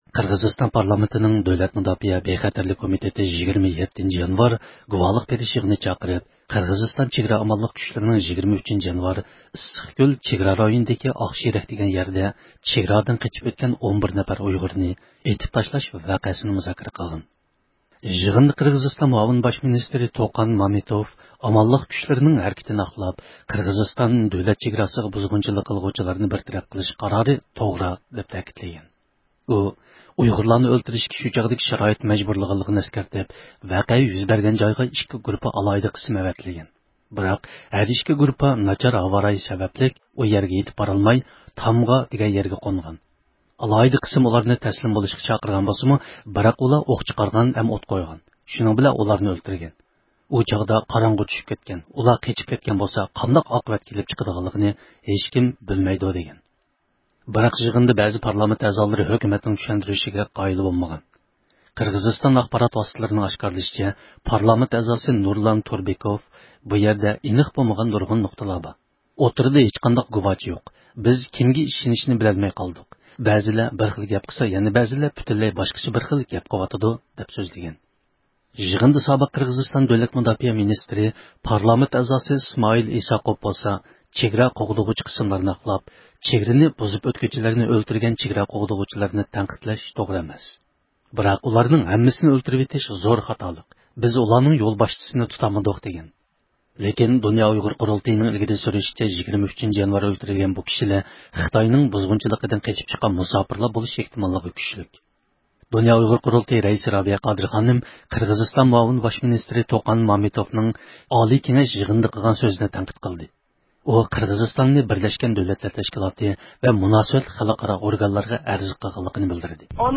رابىيە قادىر خانىم چارشەنبە كۈنى رادىئومىزغا قىلغان سۆزىدە يەنە، قىرغىزىستان ھۆكۈمىتىنى خىتايغا ماسلىشىپ، ئۇيغۇرلارغا بۇزغۇنچىلىق قىلماسلىققا ئاگاھلاندۇردى.